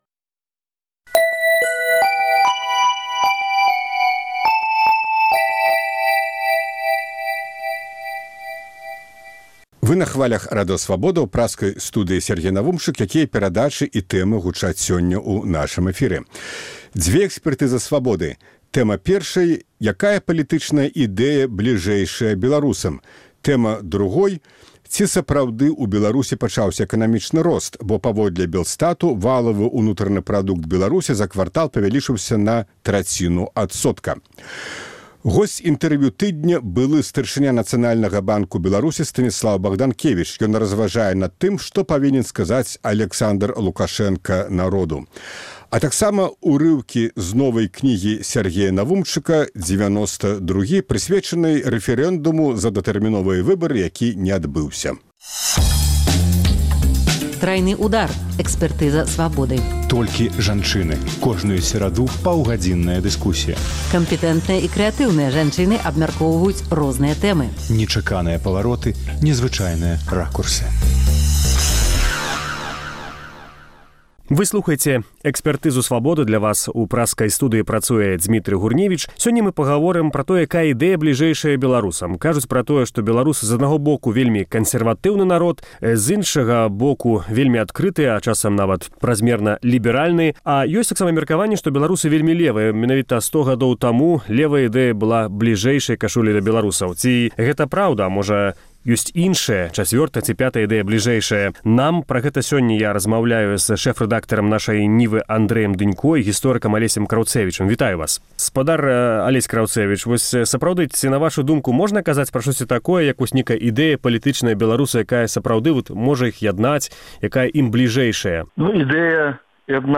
Дыскусію